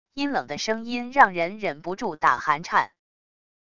阴冷的声音让人忍不住打寒颤wav音频